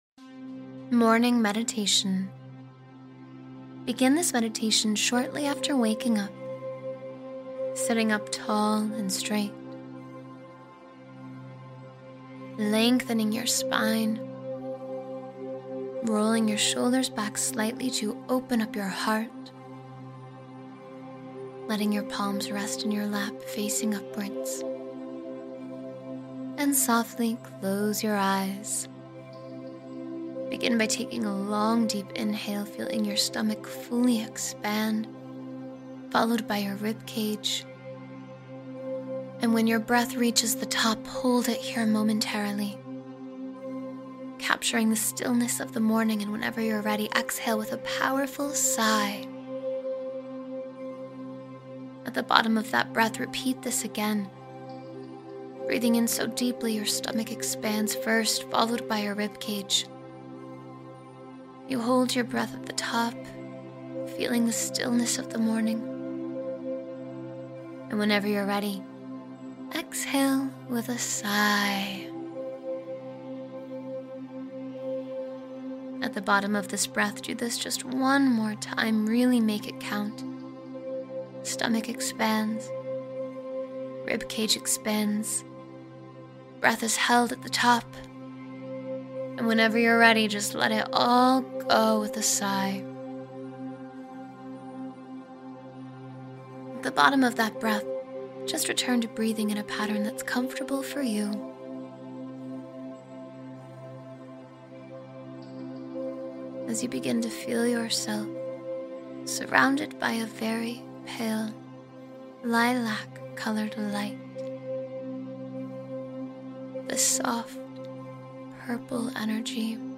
A Joyful and Uplifting Morning Meditation — Begin the Day with Pure Happiness